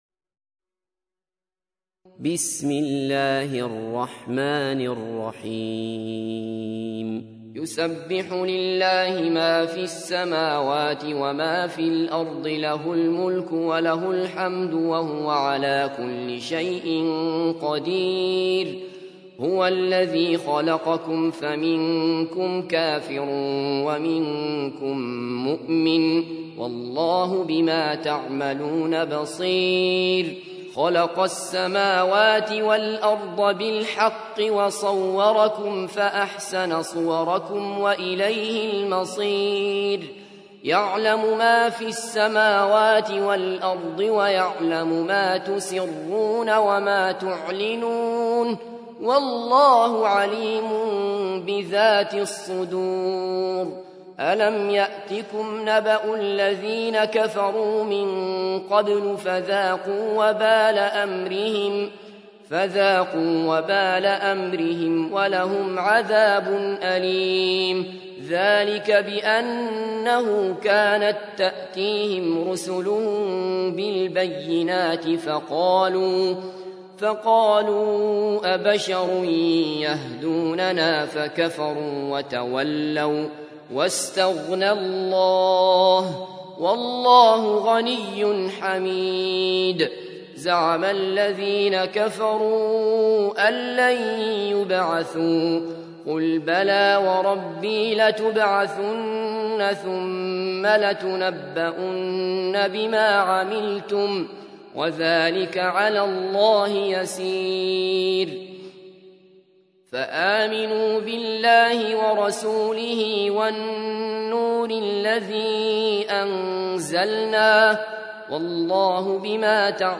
تحميل : 64. سورة التغابن / القارئ عبد الله بصفر / القرآن الكريم / موقع يا حسين